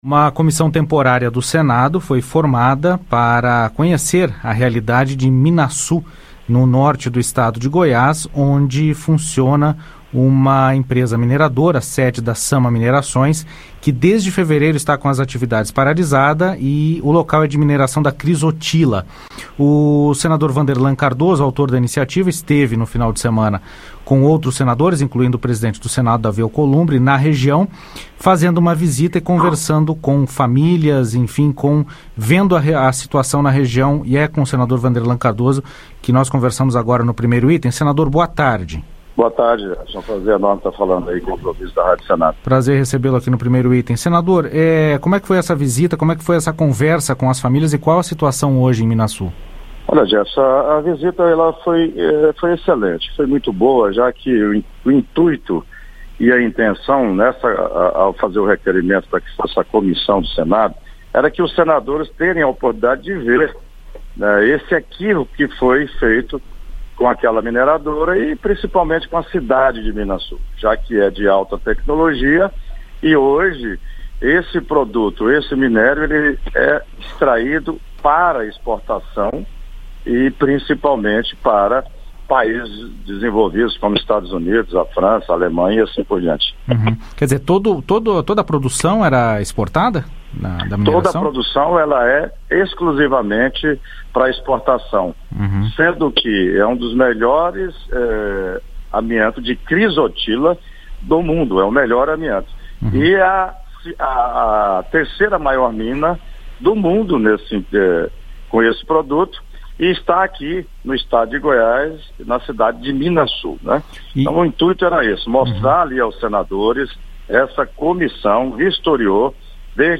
Uma comissão de senadores visitou a cidade de Minaçu, interior de Goiás, onde fica a sede da Sama Minerações. A empresa está com as atividades paralisadas desde fevereiro após uma decisão do STF em relação à produção de amianto. Sobre a visita, o senador Vanderlan Cardoso (PP-GO) concedeu entrevista